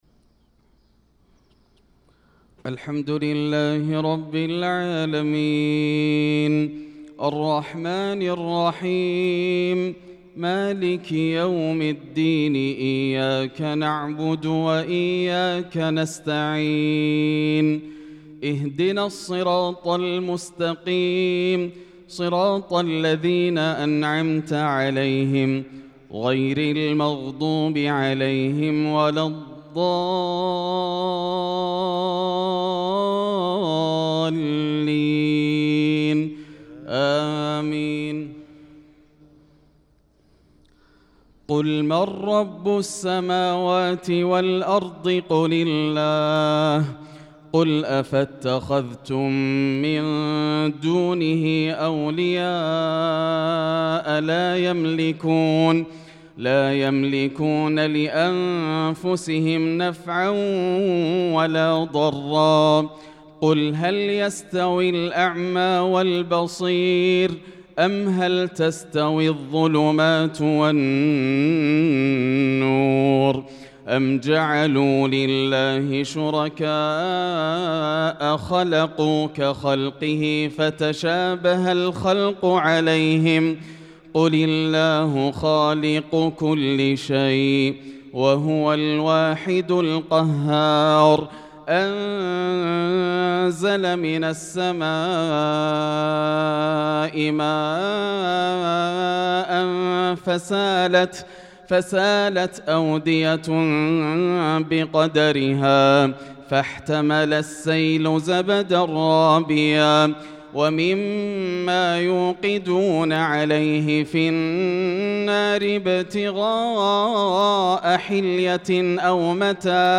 صلاة الفجر للقارئ ياسر الدوسري 24 شوال 1445 هـ
تِلَاوَات الْحَرَمَيْن .